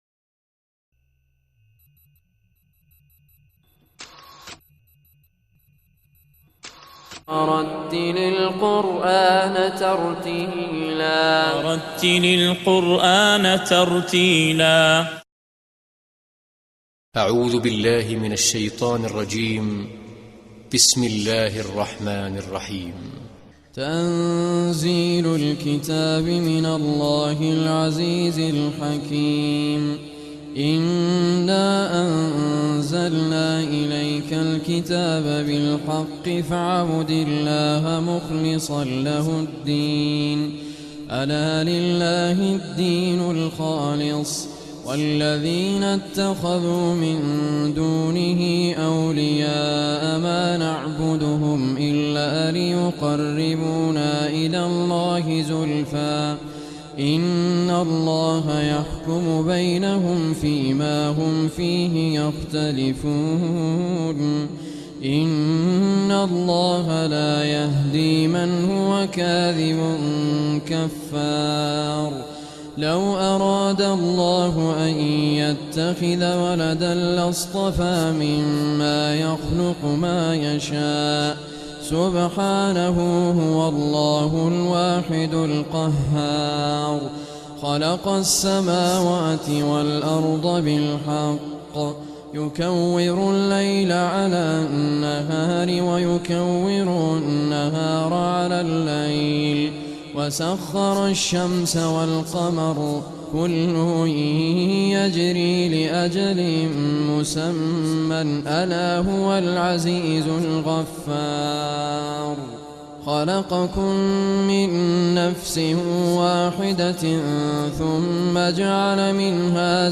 Surah Az Zumar MP3 Recitation Raad Al Kurdi